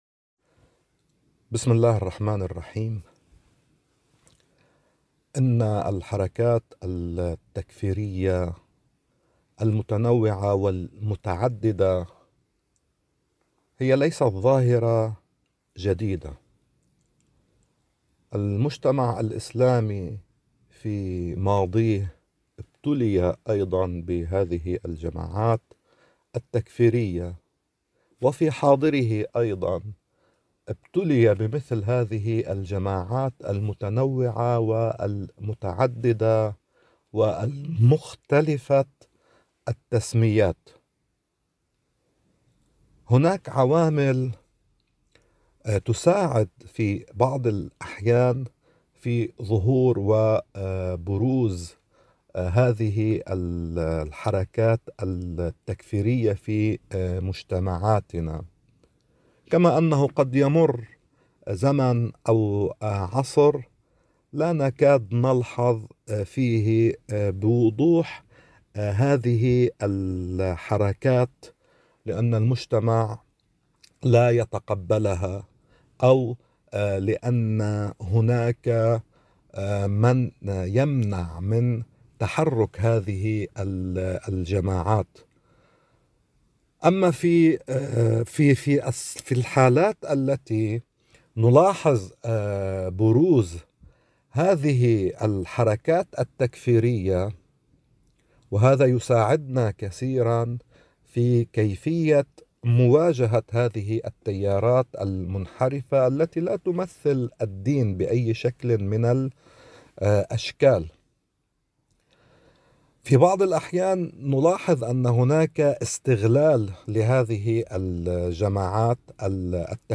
بیروت ـ إکنا: قال رجل الدین اللبناني